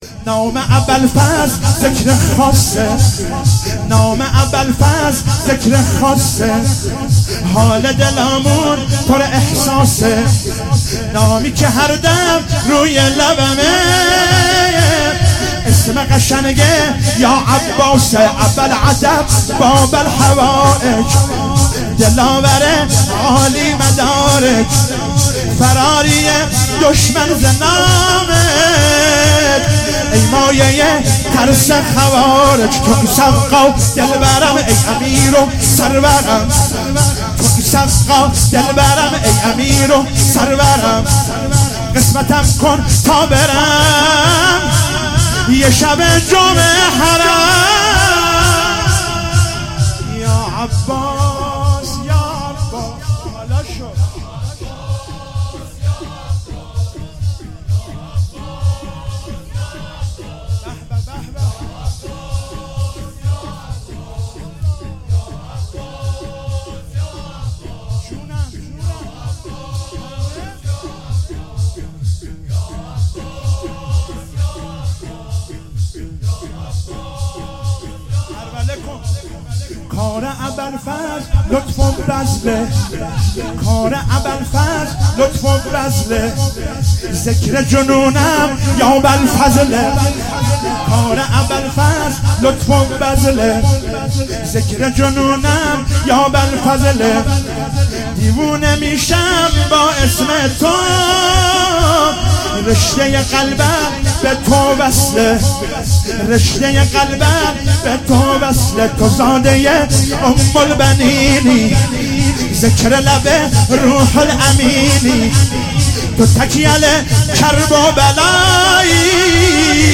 شب اول محرم97 - شور - نام ابالفضل ذکر خاصه